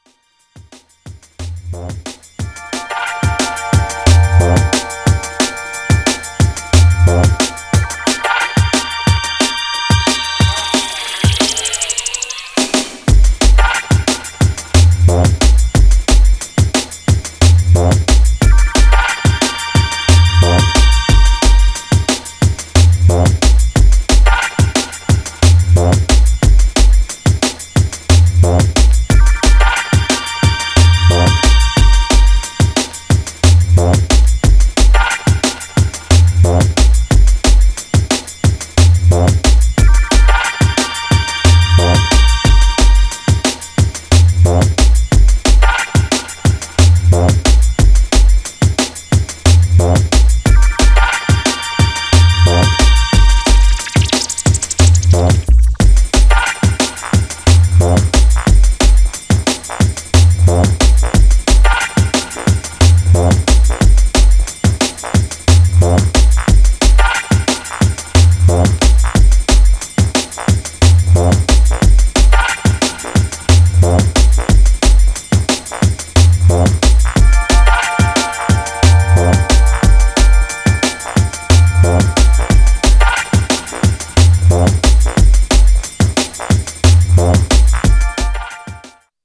Styl: Drum'n'bass, Jungle/Ragga Jungle